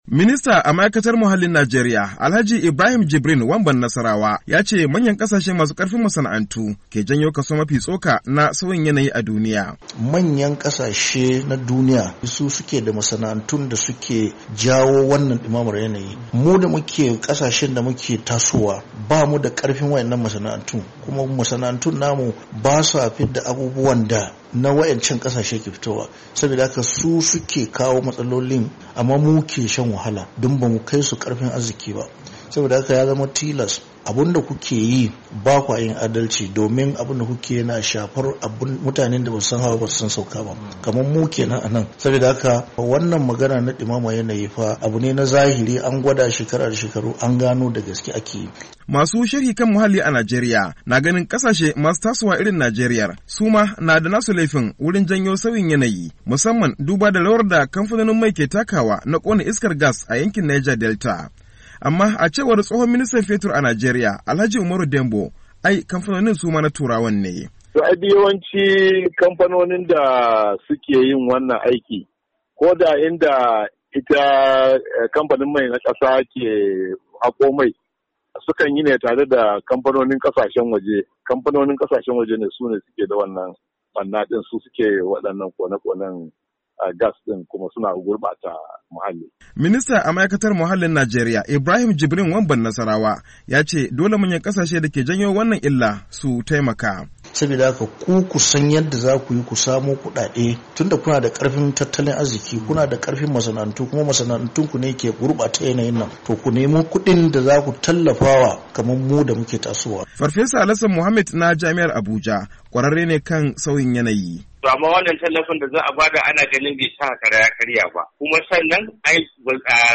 Ministan wanda ya bayyana haka a tattaunawarsa